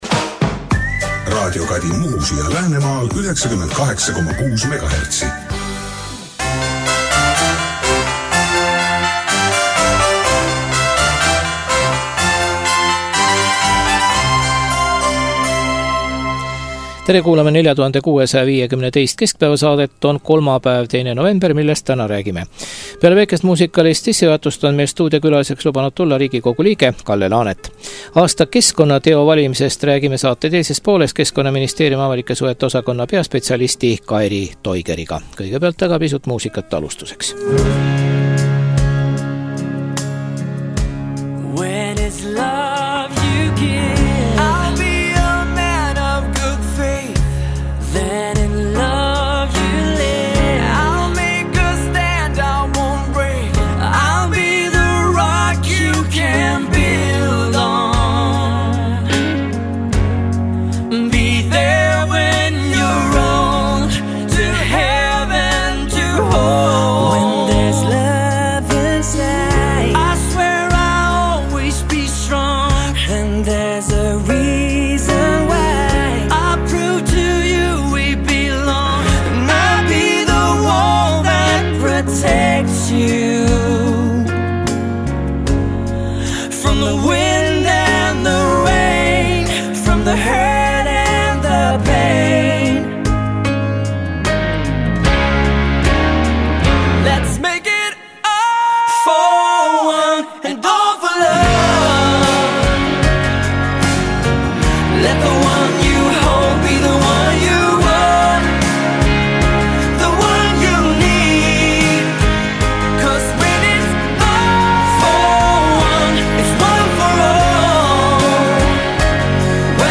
Stuudiokülaline on Riigikogu liige Kalle Laanet.